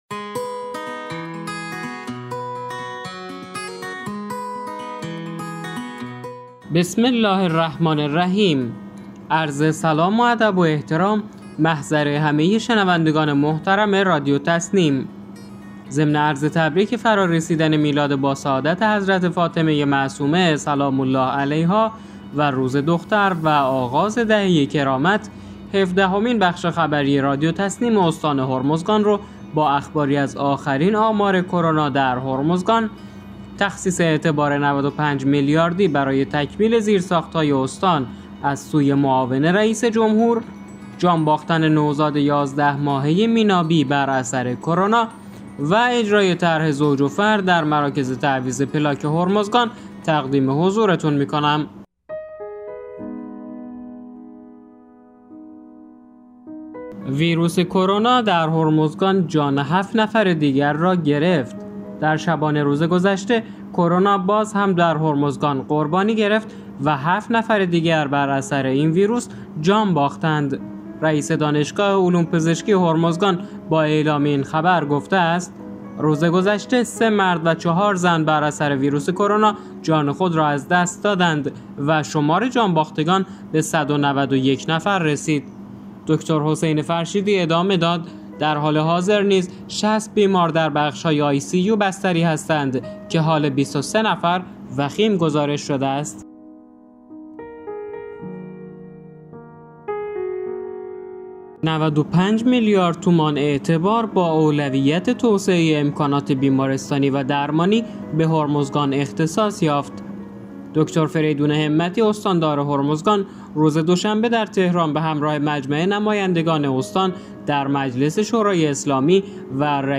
به گزارش خبرگزاری تسنیم از بندرعباس، هفدهمین بخش خبری رادیو تسنیم استان هرمزگان با اخباری از آخرین آمار کرونا در هرمزگان، تخصیص اعتبار 95 میلیاردی برای تکمیل زیرساخت‌های استان از سوی معاون رئیس جمهور، جان باختن نوزاد 11 ماهه مینابی بر اثر کرونا و اجرای طرح زوج و فرد در مراکز تعویض پلاک هرمزگان منتشر شد.